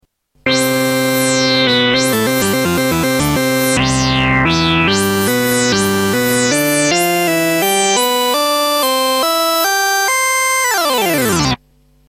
Tags: Sound Effects Orca Demos FXpansion Orca FXpansion Soft Synth